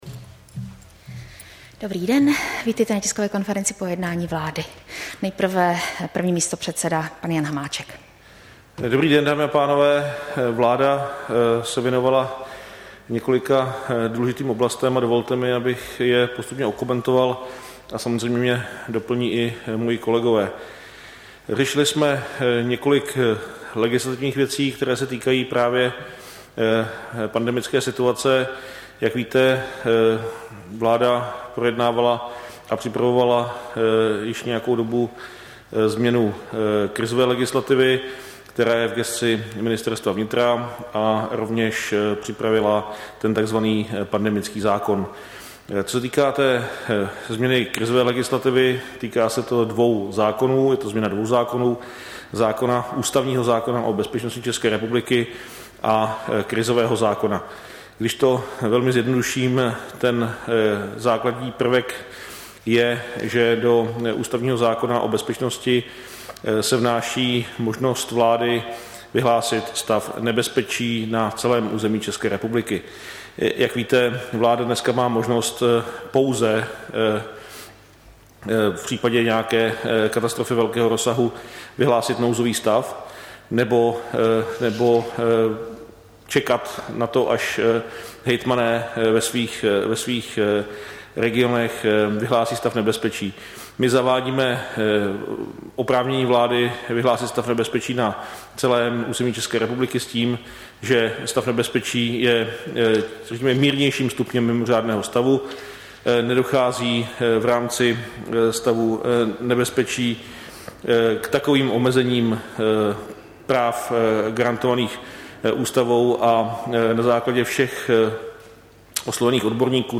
Tisková konference po jednání vlády, 15. února 2021